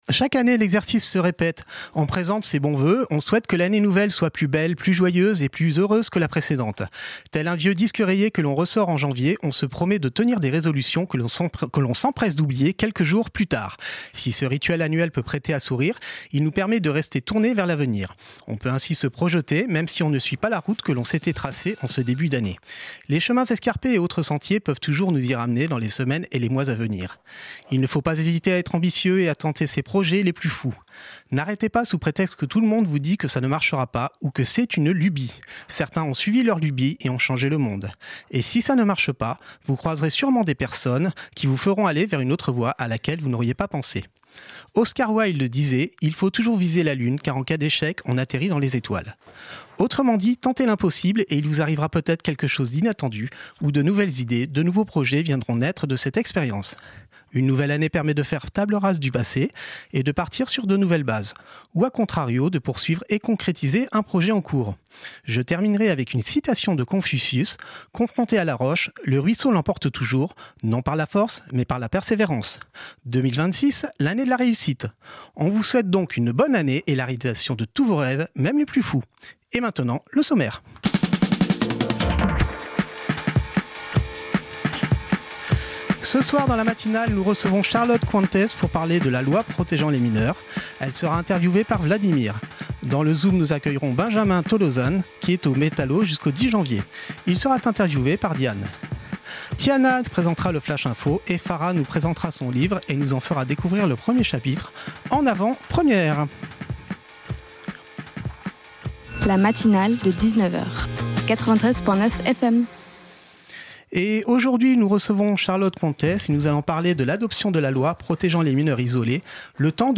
Sans-abrisme chez les mineurs et les accents régionaux au théâtre Partager Type Magazine Société Culture mercredi 7 janvier 2026 Lire Pause Télécharger Ce soir